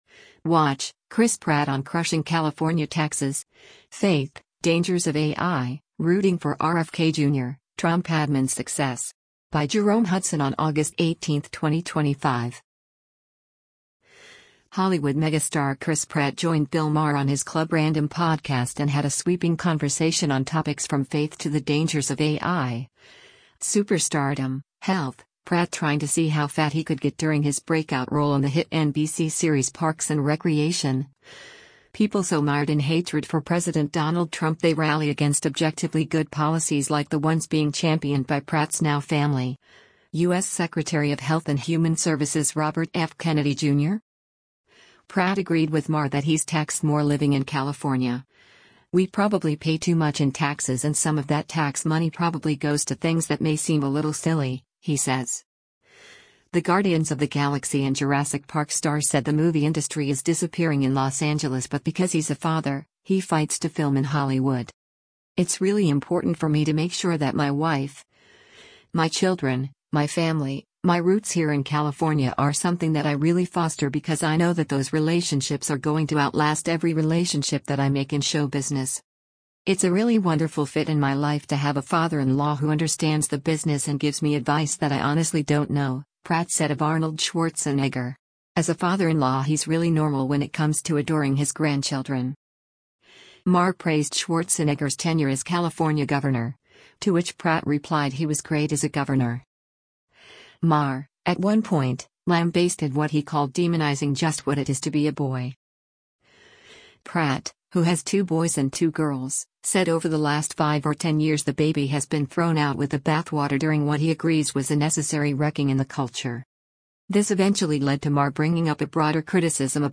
Hollywood megastar Chris Pratt joined Bill Maher on his Club Random podcast and had a sweeping conversation on topics from faith to the dangers of A.I., superstardom, health — Pratt trying to see “how fat” he could get during his breakout role on the hit NBC series Parks and Recreation — people so “mired in hatred” for President Donald Trump they rally against objectively good policies like the ones being championed by Pratt’s now-family, U.S. Secretary of Health and Human Services Robert F. Kennedy Jr.